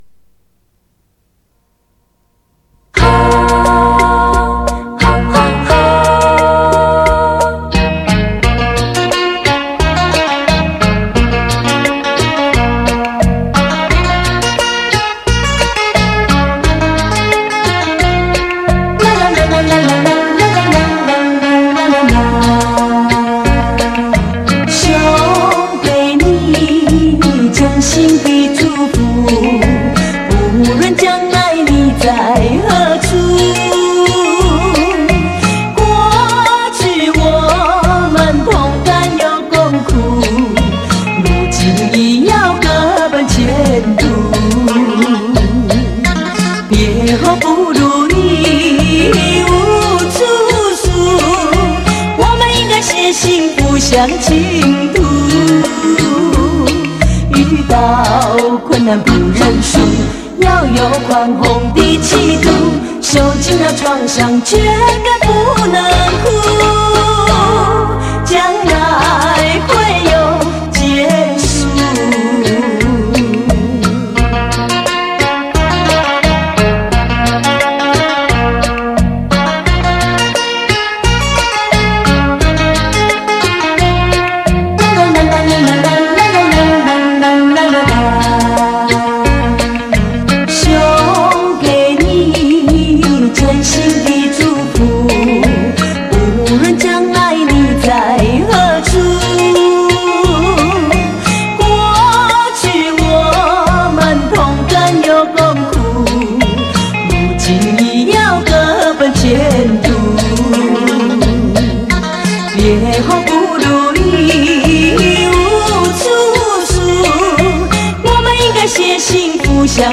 磁带数字化：2022-11-25
温婉甜美 婉婉动听 不愧是甜歌皇后